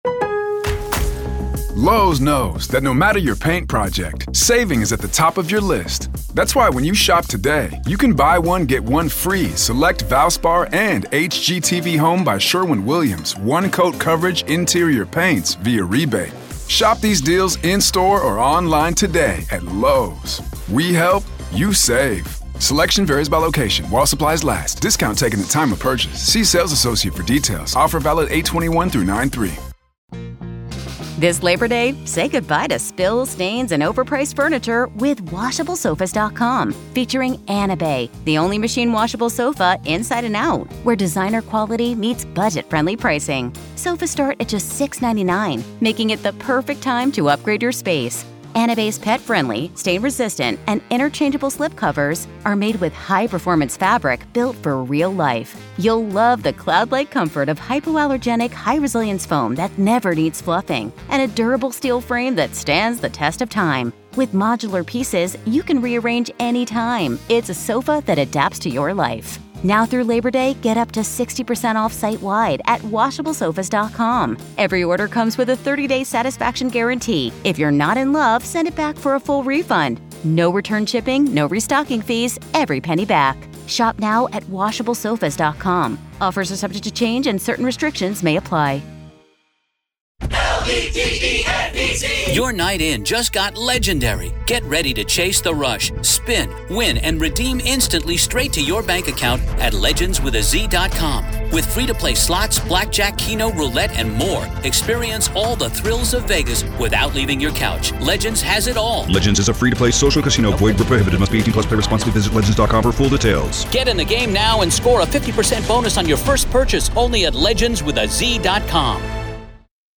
Tune in for a captivating conversation that explores the complexities and nuances of the Daybell case and the legal tactics at play.